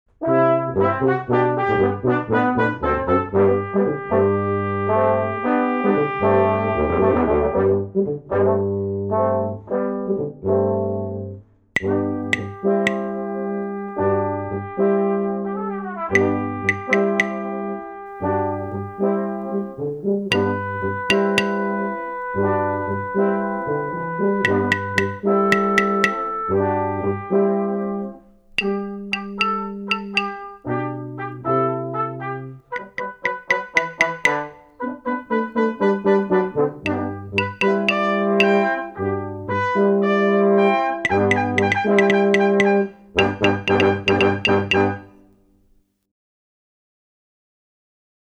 Klapoefening 1 met koperkwintet